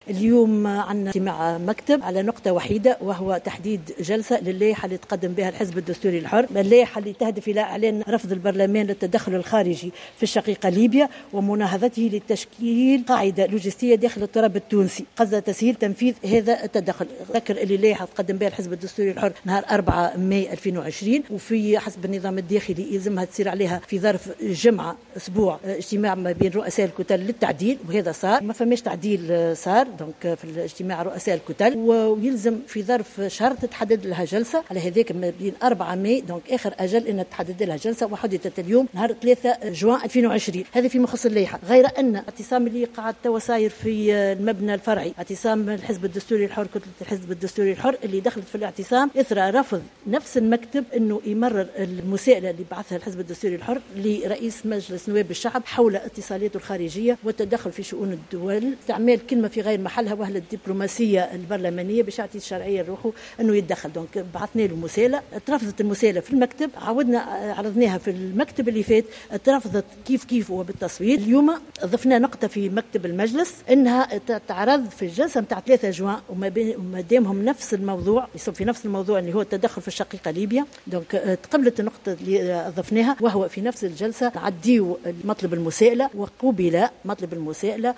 وأكدت عضو مكتب المجلس سميرة السايحي في تصريح للجوهرة أف أم ، أنه سيتم أيضا ا لنظر في لائحة تقدمت بها كتلة الحزب الدستوري الحرّ تهدفُ لإعلان رفض البرلمان للتدخّل الخارجي في ليبيا ومناهضته لتشكيل قاعدة لوجستيّة داخل التراب التونسي قصد تسهيل تنفيذ هذا التدخّل.